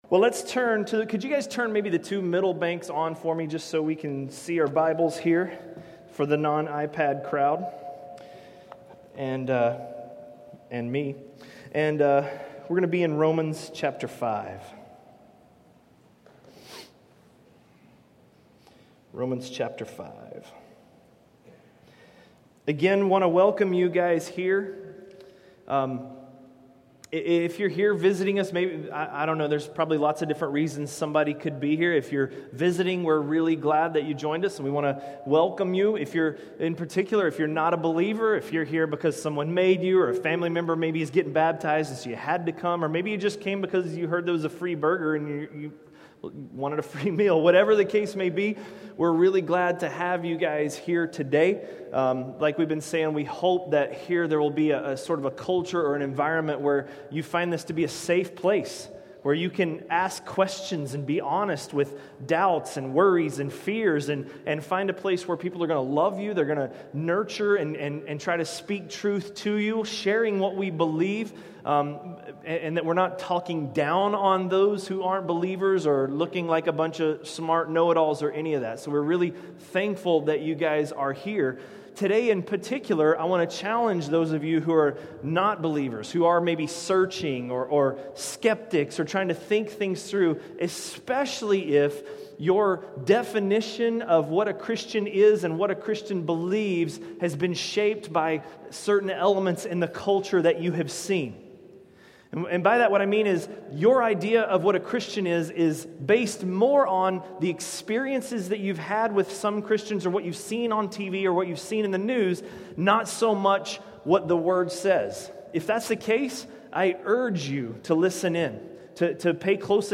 A message from the series "Romans." Romans 5:6–5:8